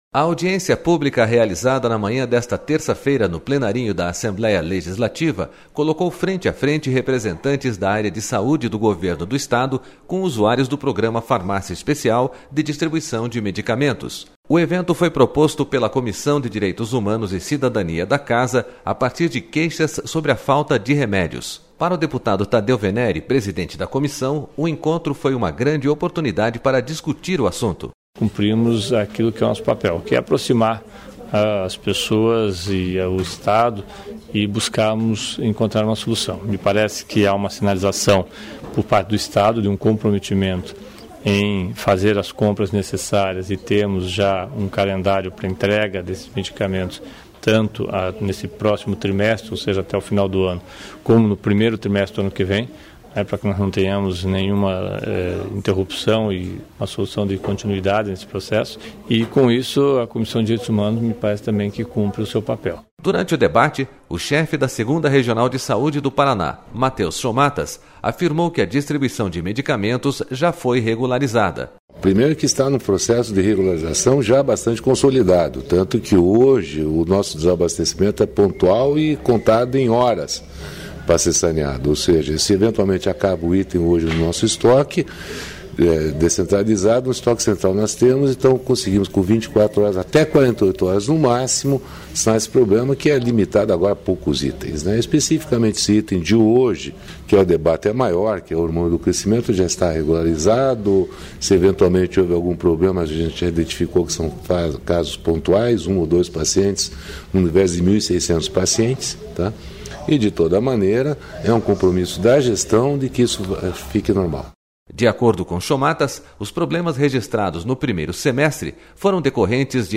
A audiência pública realizada na manhã desta terça-feira, no Plenarinho da Assembleia Legislativa, colocou frente a frente representantes da área da Saúde do Governo do Estado com usuários do programa Farmácia Especial, de distribuição de medicamentos.//O evento foi proposto pela Comissão de Direito...